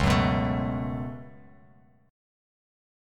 Db7sus4#5 chord